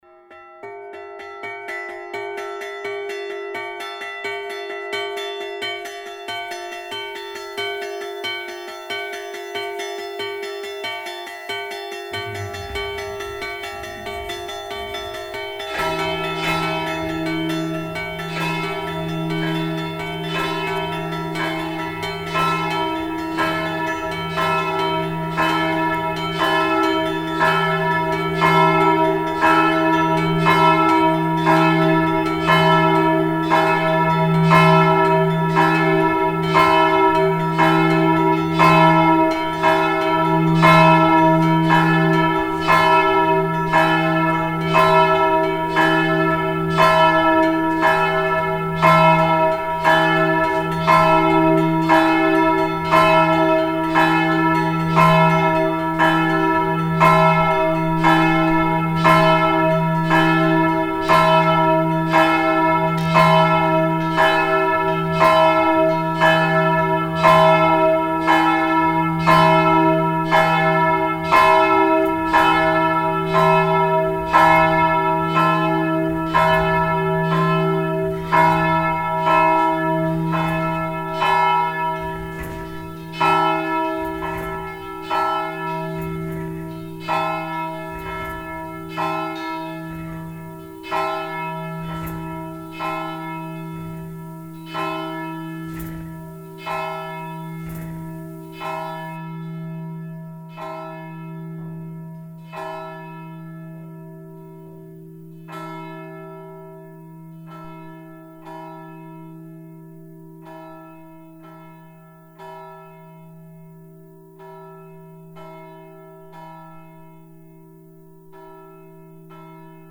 Perception that is extended by technology, by microphones and headphones, perception that is additionally extended by focused ears gathered to listen and to hear.
Sometimes it is an echo of a beautifully round shaped sound of thunder that – for a moment – fills all the corners of stone streets.
Echos are like humans, alive in halls and church towers, old, slow, clean, defined, very conservative, but very interested in new movements that are always measured by time.